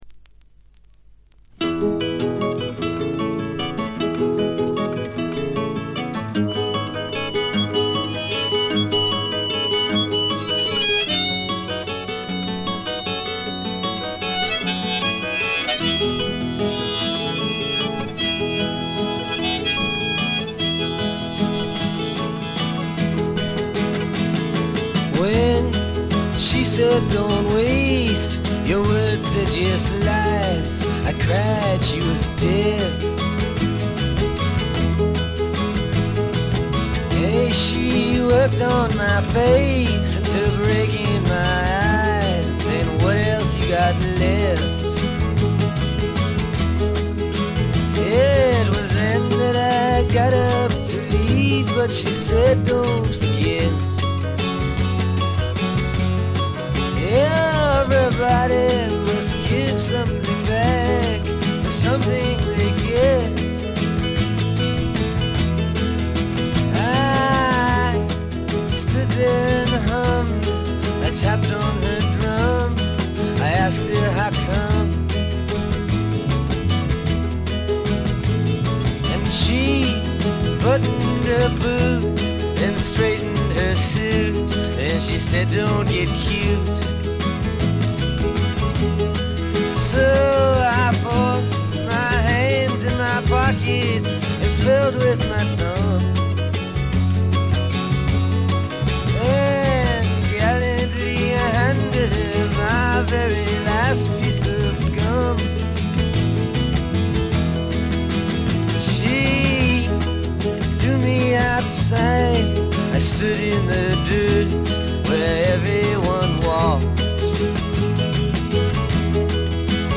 モノラルの濃厚な味わいを堪能できます。
試聴曲は現品からの取り込み音源です。
vocals, guitar, harmonica, piano